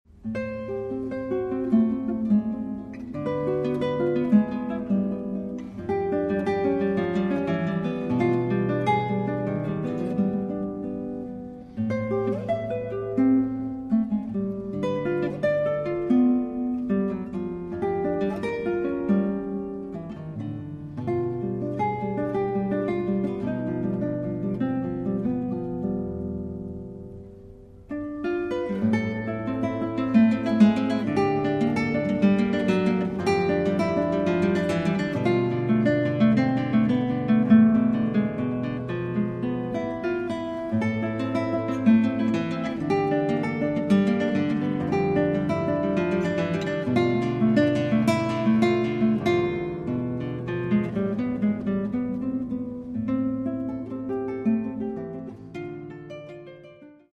New Zealand classical guitarist composer